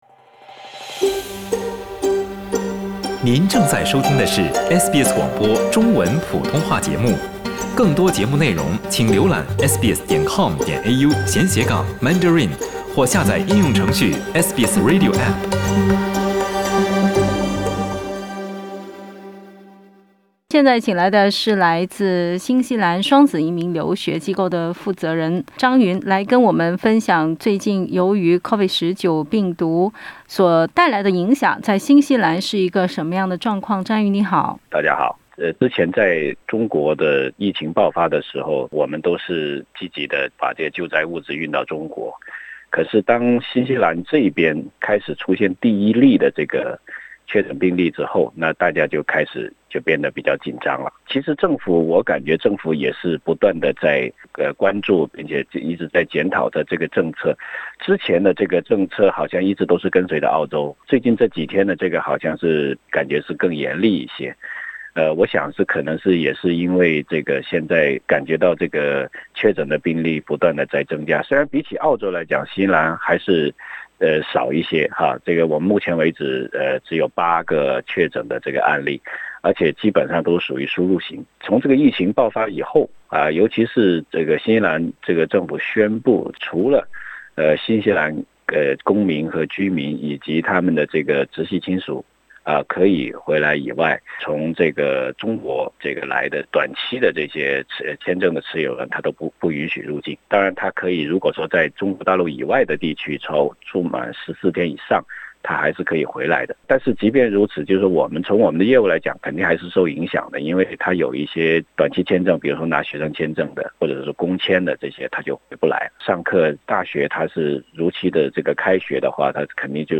他們如何購買到合格的物資，又如何保證醫療物資到達一線醫護人員的手中？ 點擊上方圖片收聽錄音寀訪。